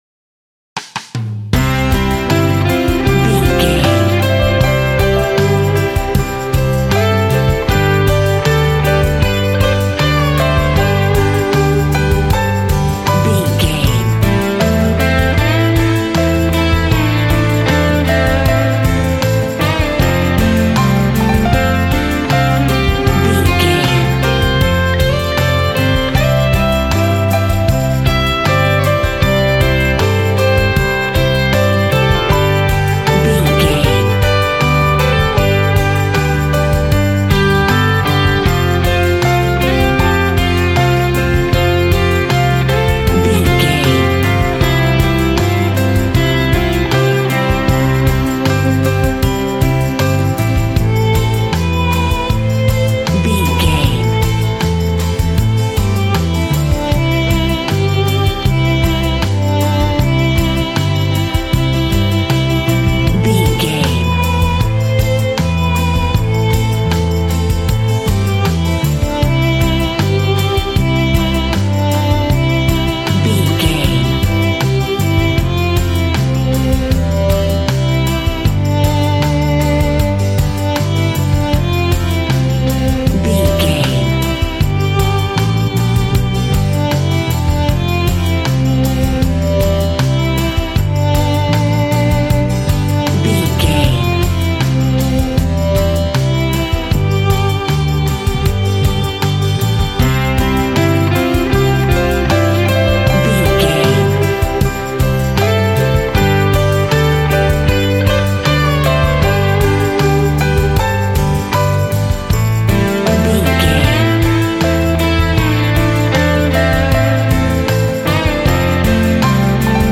Ionian/Major
cheerful/happy
double bass
drums
piano